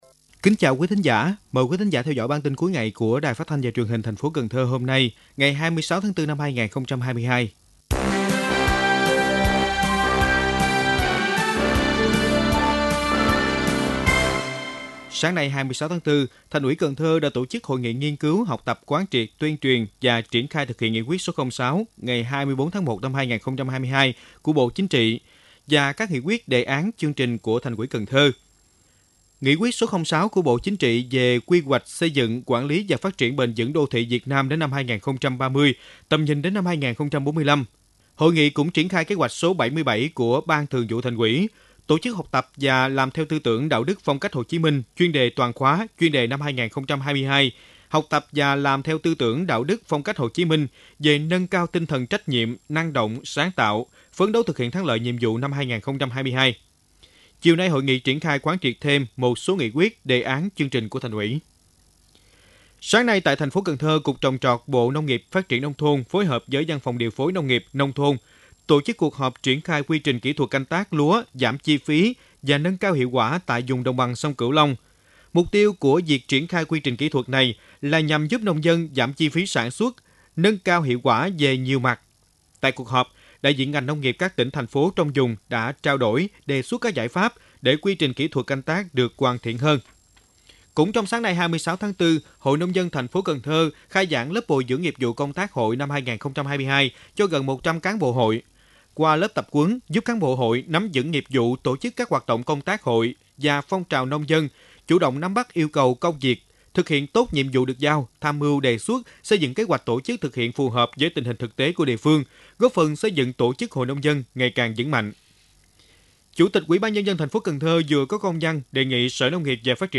Bản tin cuối ngày 26/4/2022